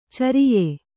♪ cariye